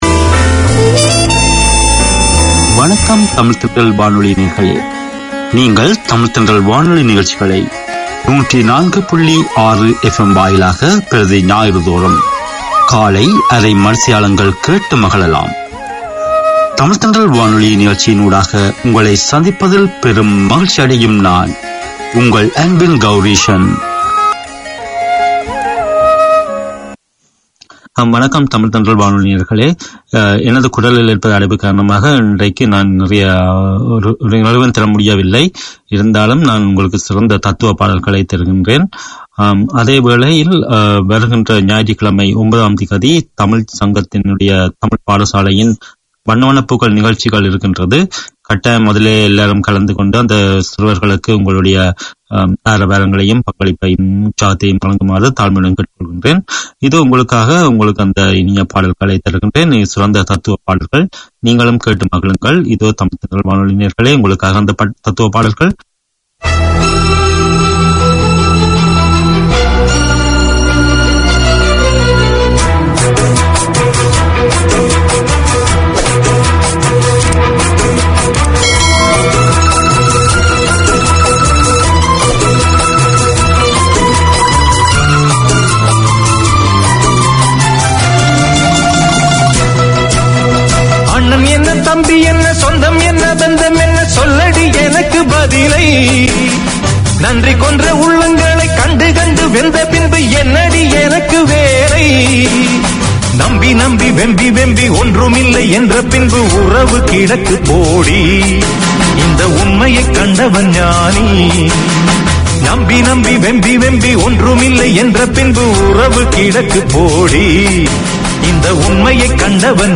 Tamil literature, poems, news and interviews - Melisai brings you current affairs, local and international events relevant to Tamils, with wit and humour. Each Sunday morning there’s the chance to hear local Tamil perspectives presented by Tamil speakers with a passion for the language and culture. The music is varied, the topics entertaining.